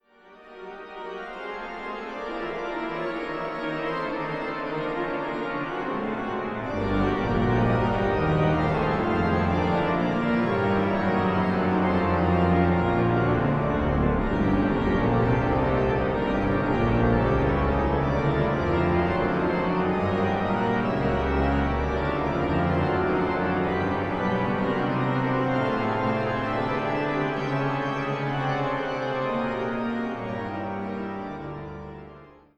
an der Orgel der Jesuitenkirche St. Michael zu München